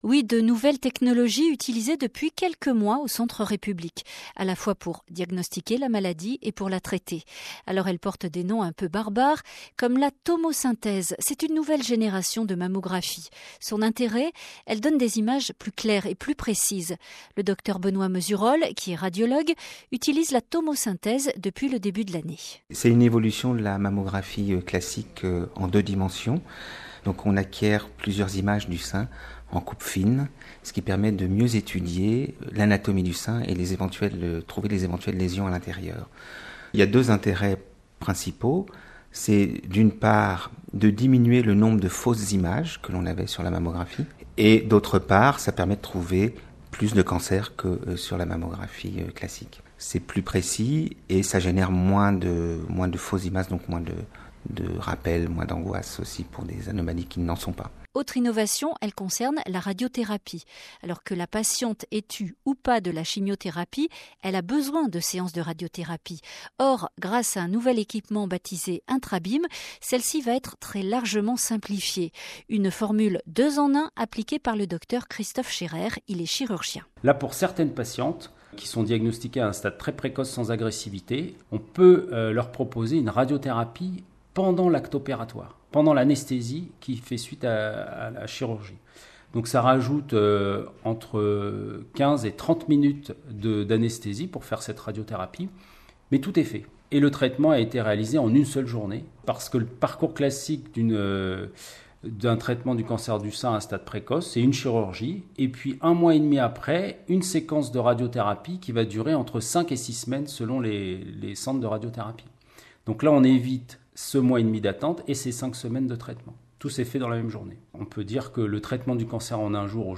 Interviews réalisés par Radio France Pays d’Auvergne,